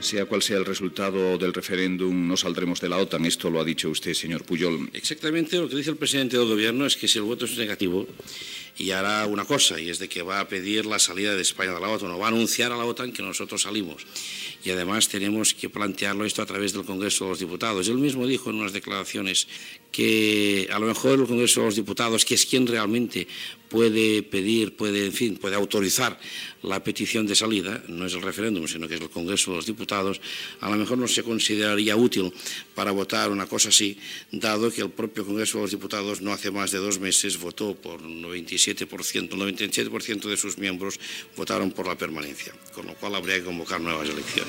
Fragment d'una entrevista a Jordi Pujol sobre el referèndum de la OTAN
Info-entreteniment